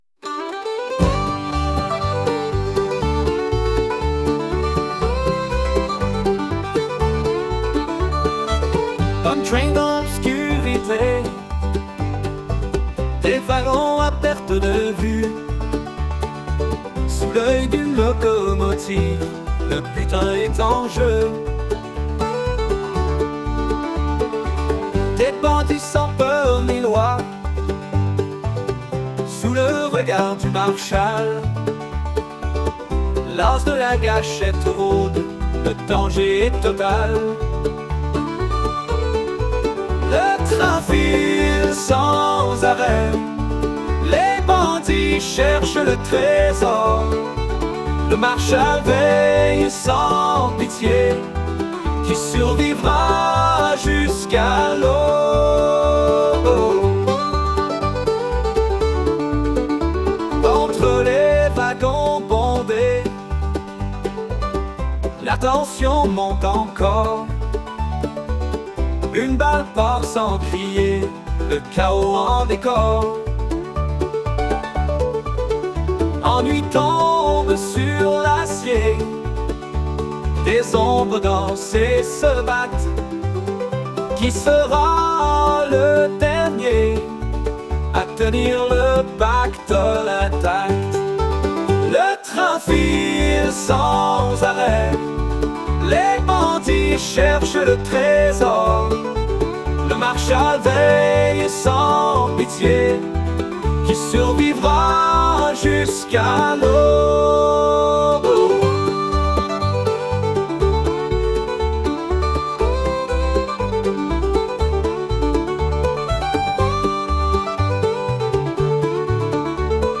Yep, Azul ambiance disco / funk !